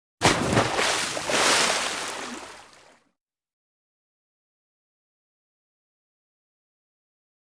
Large Splash Or Dive Into Water 4.